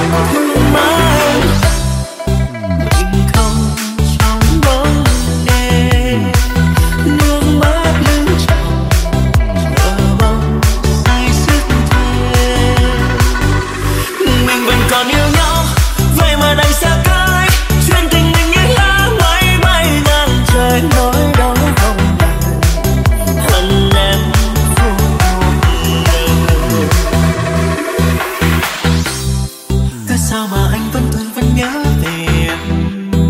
Remix TikTok